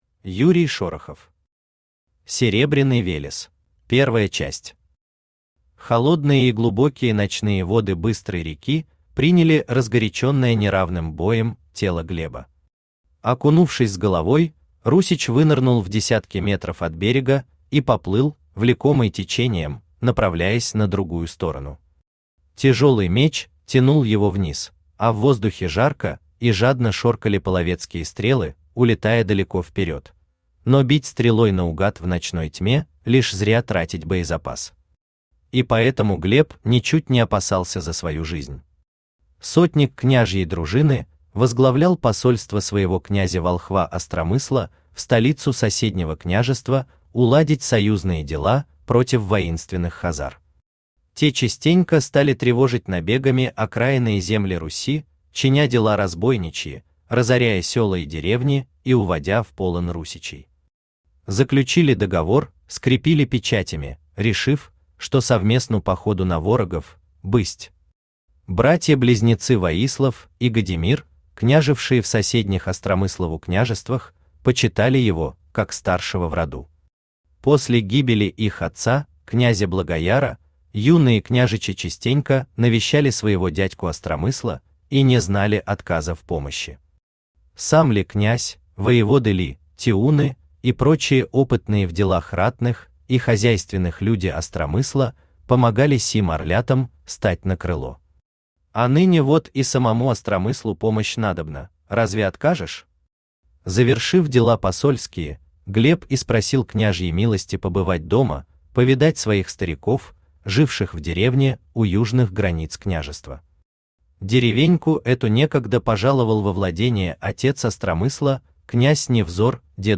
Аудиокнига Серебряный Велес | Библиотека аудиокниг
Aудиокнига Серебряный Велес Автор Юрий Шорохов Читает аудиокнигу Авточтец ЛитРес.